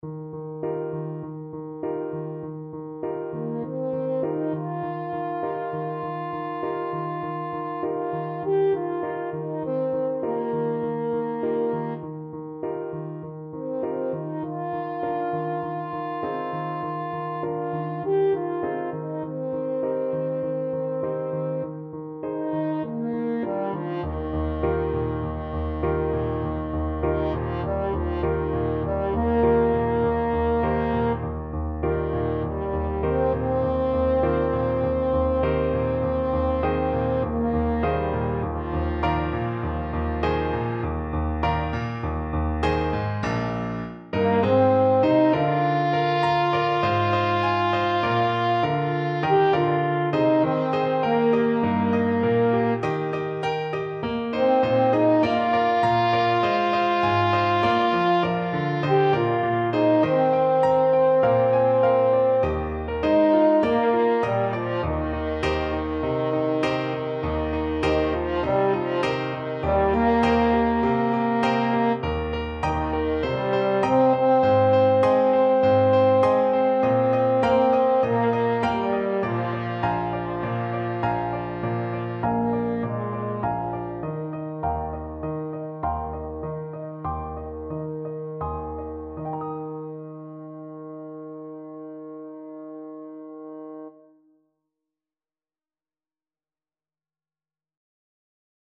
4/4 (View more 4/4 Music)
Moderato =c.100